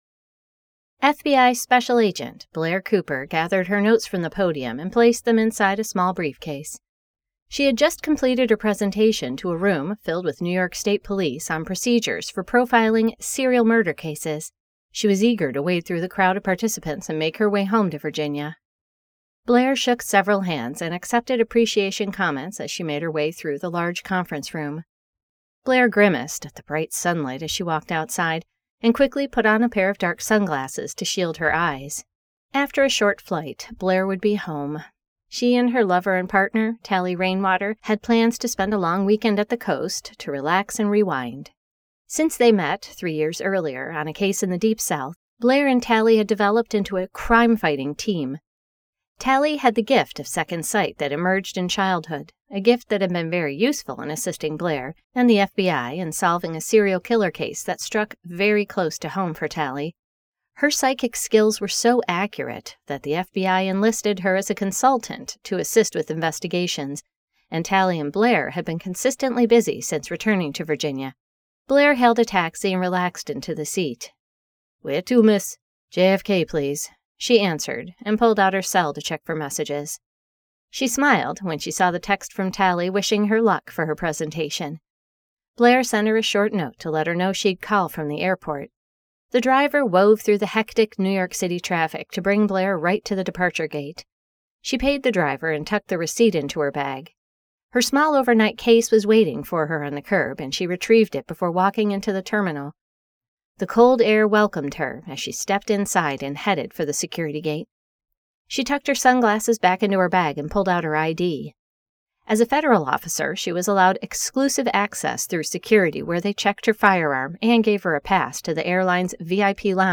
The Ghost of East Texas by Ali Spooner [Audiobook]
ghost-of-east-texas-five-minute-promo.mp3